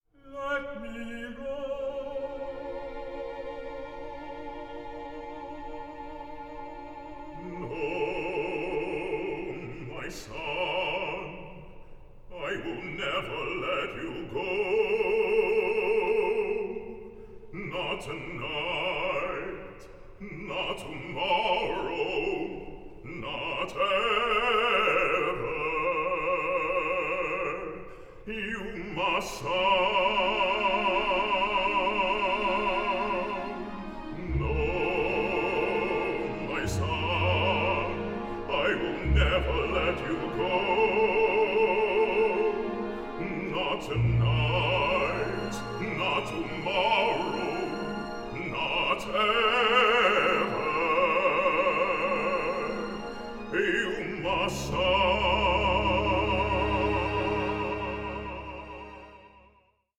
a contemporary opera on racial injustice in the US today